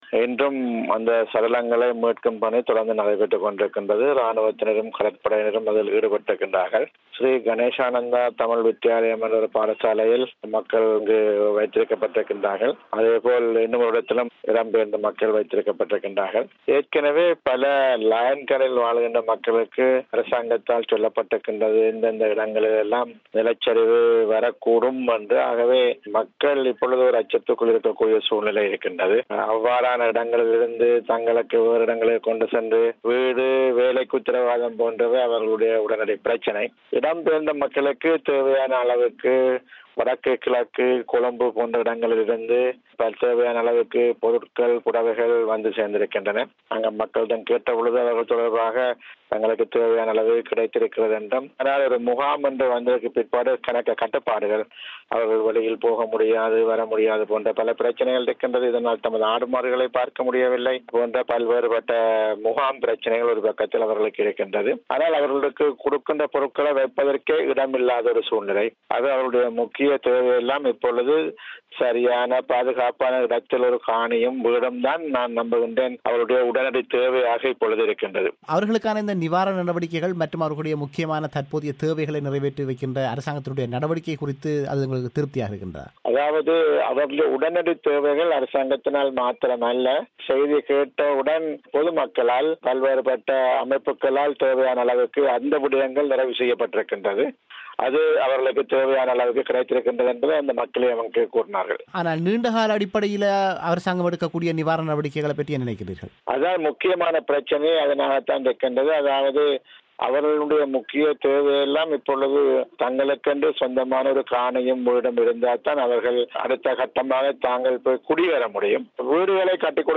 அவரது முழுமையான செவ்வியை நேயர்கள் இங்கு கேட்கலாம்.